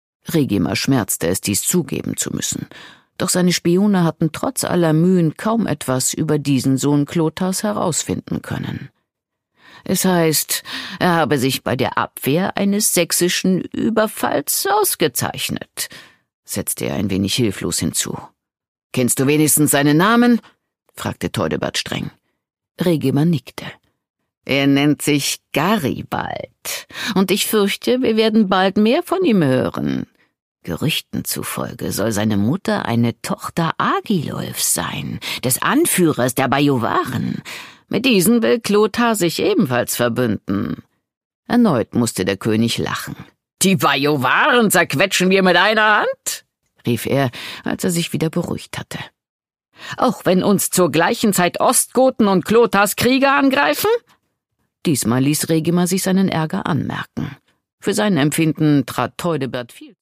Produkttyp: Hörbuch-Download
gewohnt mitreißend und mit warmer, melodiöse Stimme.